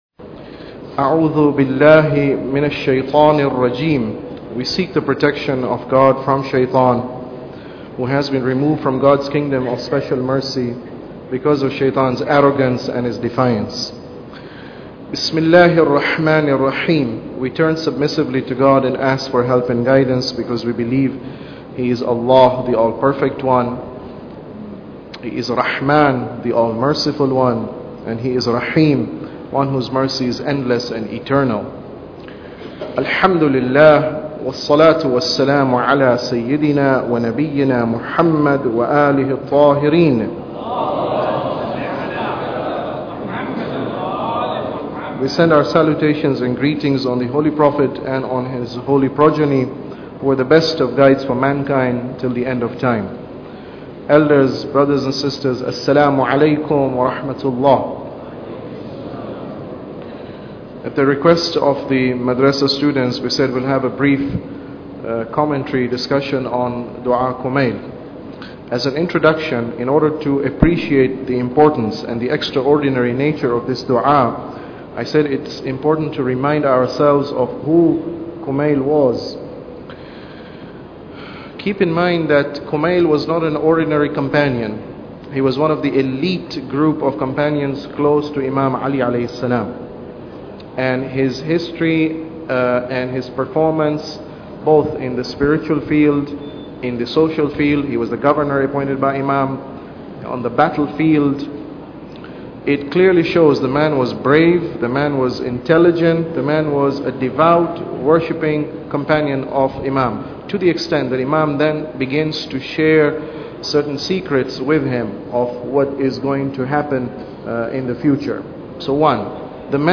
Tafsir Dua Kumail Lecture 3